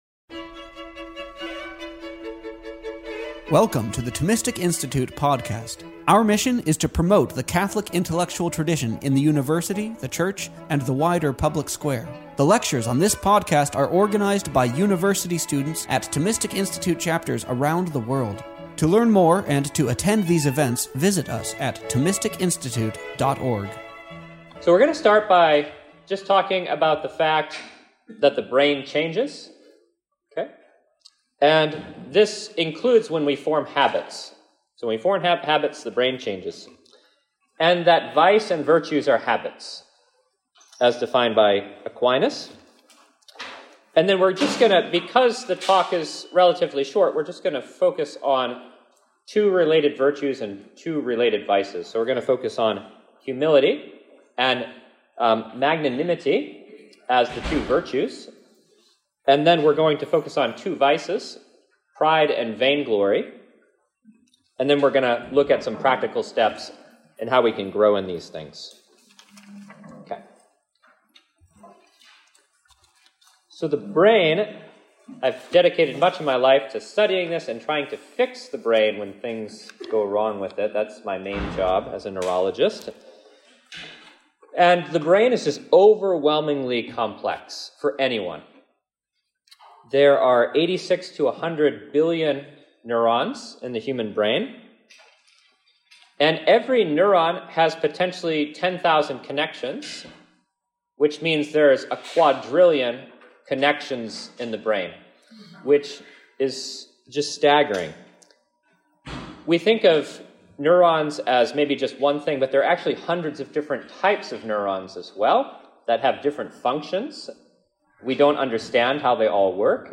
The Thomistic Institute Podcast features the lectures and talks from our conferences, campus chapters events, intellectual retreats, livestream events, and much more.